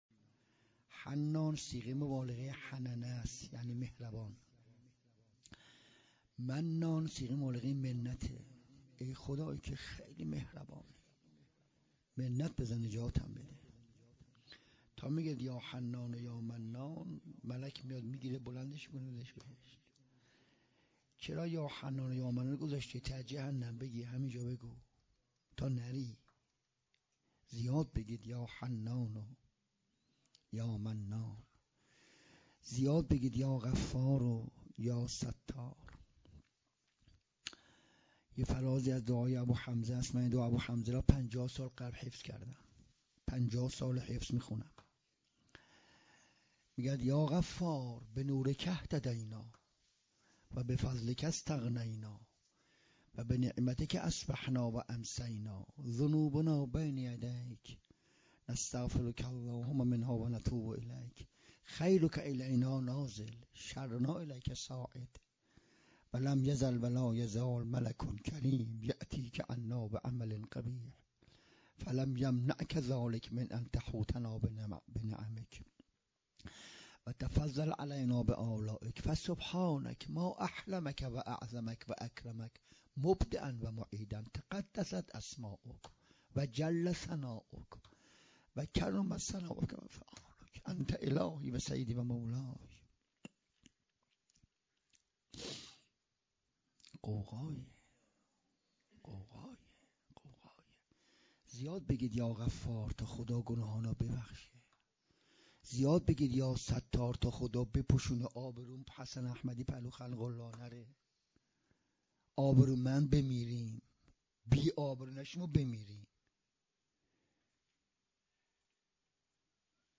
28 اردیبهشت 97 - هیئت ام ابیها - اثبات ولایت امیرالمومنین
سخنرانی